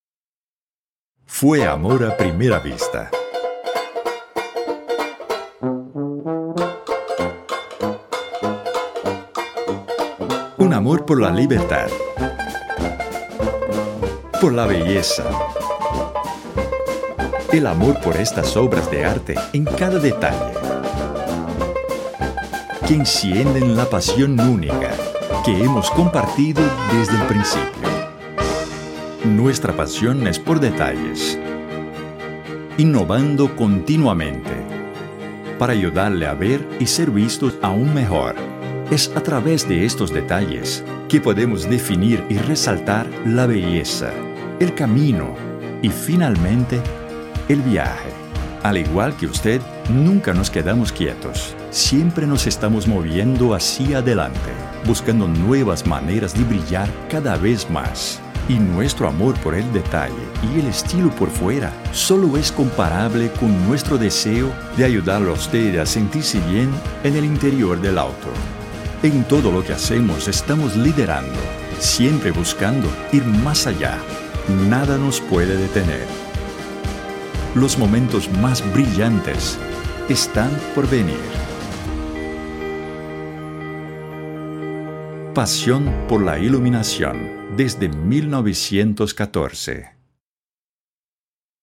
Locução em espanhol para vídeo da Philips.